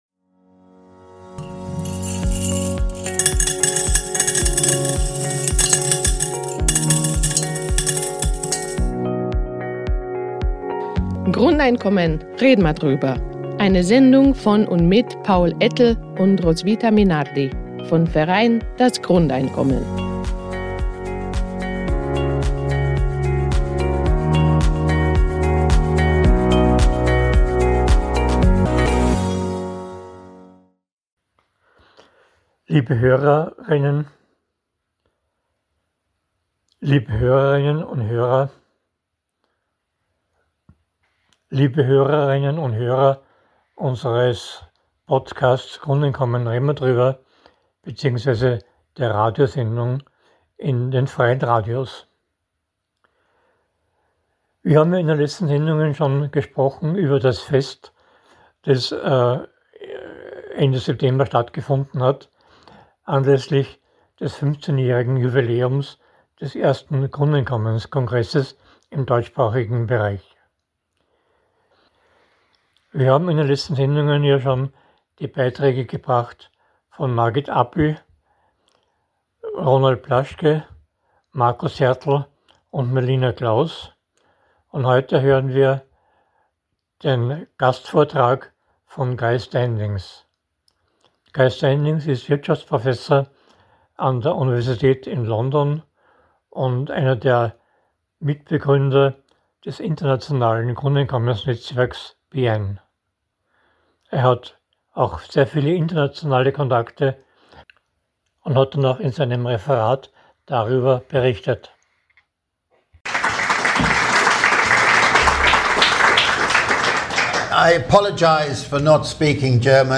Er ist Mitbegründer des internationalen Grundeinkommensnetzwerks BIEN und hält in vielen Ländern Vorträge. Bei unserem Fest anläßlich 20 Jahre Grundeinkommenskongress iin Wien hat er darüber berichtet - unter anderem über seine Kontakte mit dem kürzlich neu gewählten Präsidenten von Südkorea.